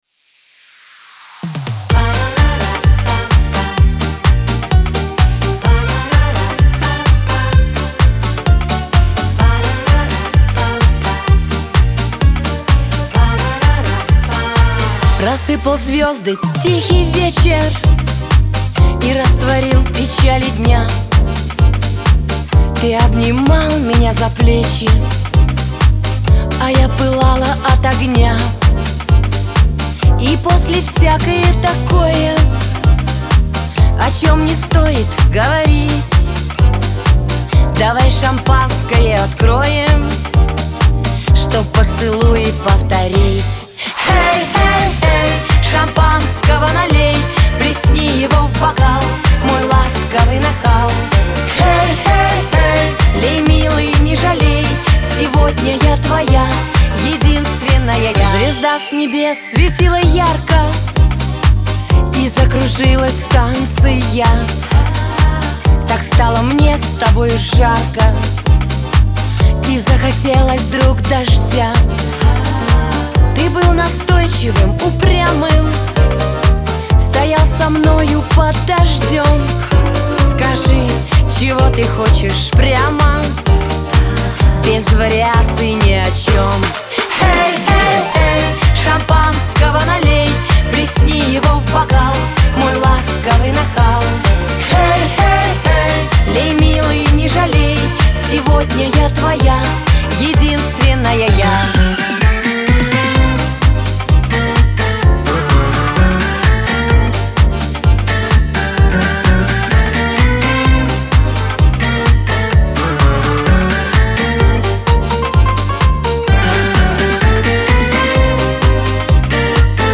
танцевальная музыка
эстрада , Веселая музыка , диско